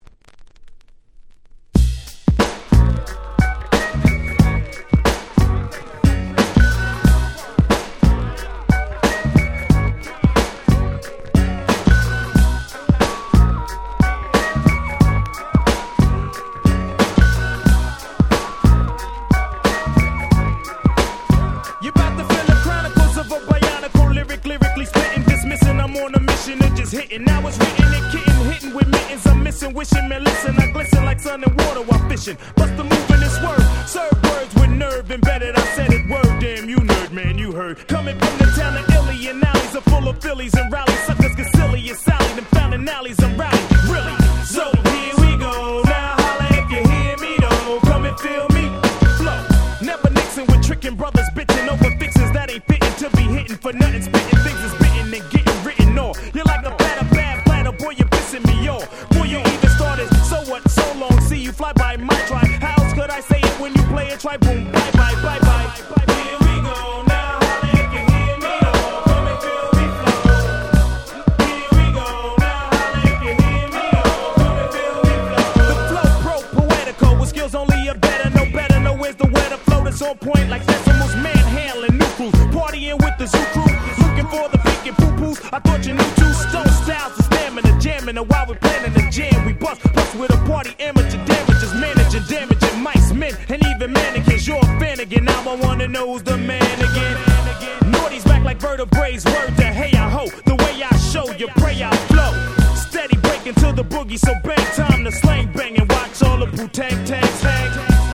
95' Hip Hop Classic !!
チルアウトしつつも何だか楽しい感じ？